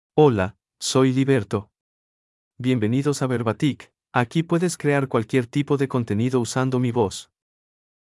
MaleSpanish (Mexico)
Liberto — Male Spanish AI voice
Liberto is a male AI voice for Spanish (Mexico).
Voice sample
Listen to Liberto's male Spanish voice.
Liberto delivers clear pronunciation with authentic Mexico Spanish intonation, making your content sound professionally produced.